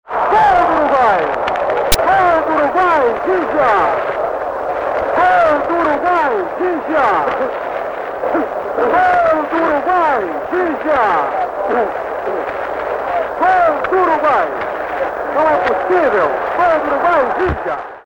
Luiz Mendes dá voz à incredulidade dos brasileiros perante o golo de Ghiggia, que carimba a surpreendente vitória dos uruguaios na final do Mundial de 1950. A partida fica conhecida como Maracanaço.
Segundo gol do Uruguai na final Copa do Mundo 1950 - Narração de Luiz Mendes (Rádio Globo)-thttvWisKbk.mp4